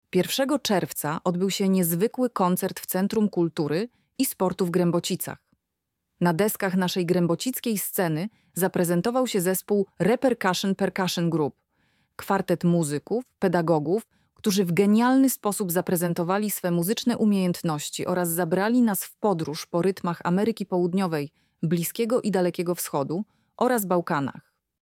lektor-repercussion.mp3